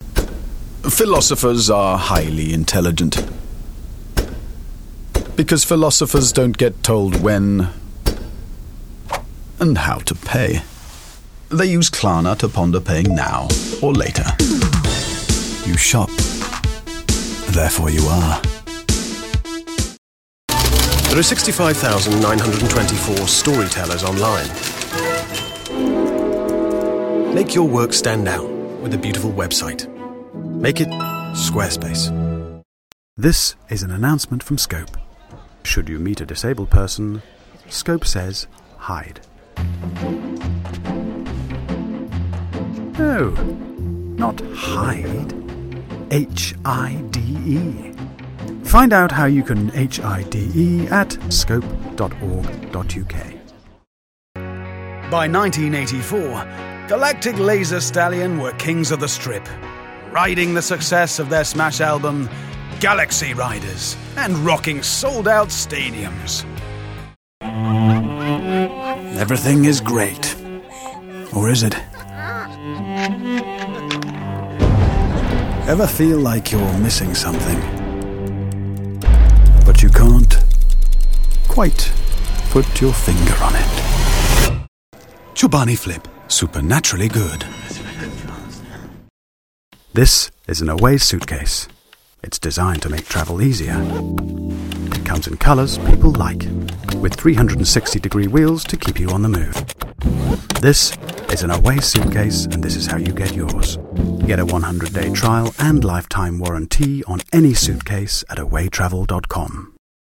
Audiobook Demo